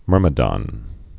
(mûrmə-dŏn, -dn)